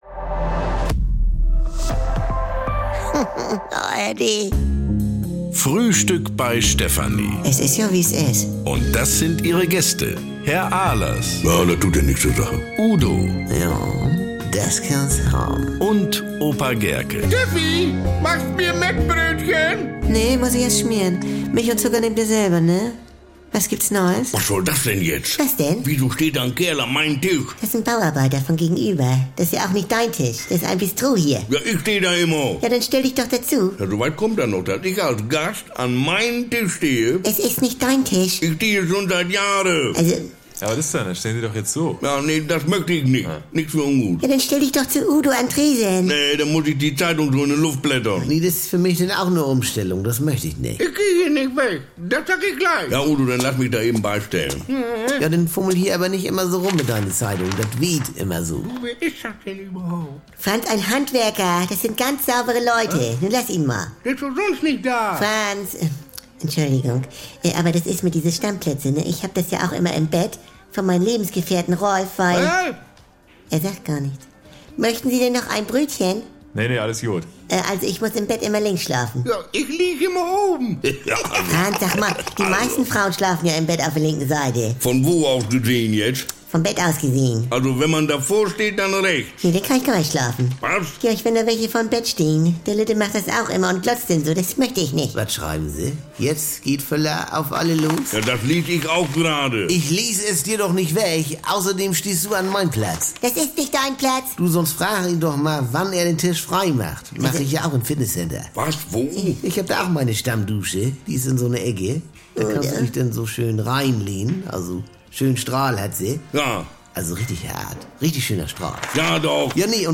Garantiert norddeutsch mit trockenen Kommentaren, deftigem Humor und leckeren Missverständnissen.